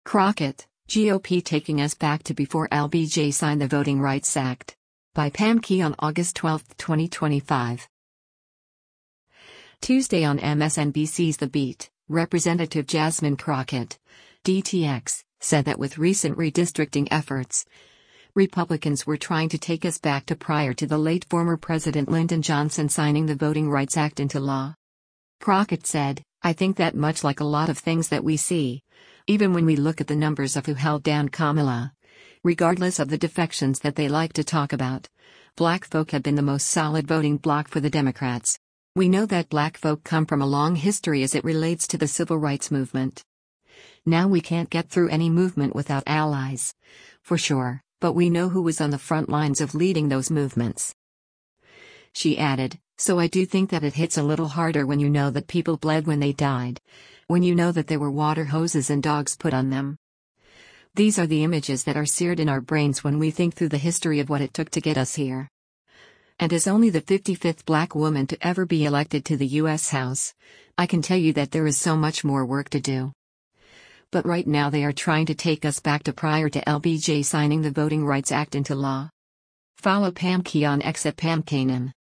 Tuesday on MSNBC’s “The Beat,” Rep. Jasmine Crockett (D-TX) said that with recent redistricting efforts, Republicans were “trying to take us back to prior to the late former President Lyndon Johnson signing the Voting Rights Act into law.”